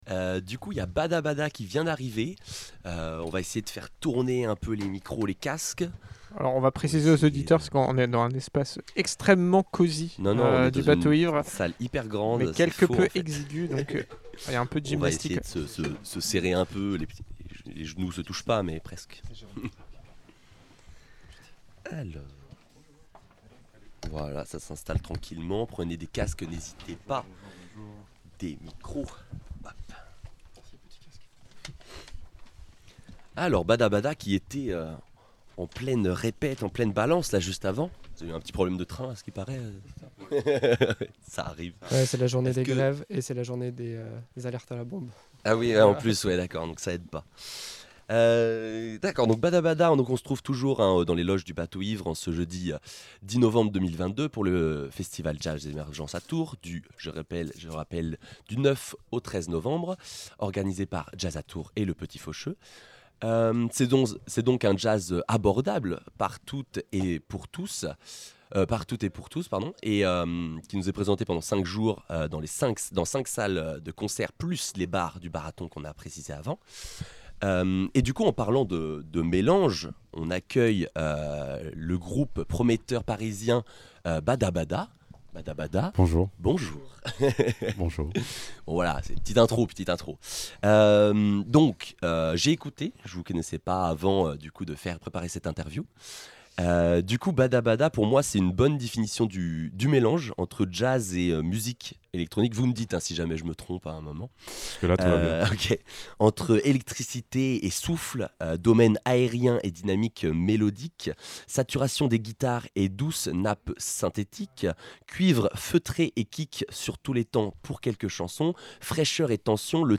La team Campus installe son studio au Bateau Ivre pour une émission spéciale sur le festival Émergences !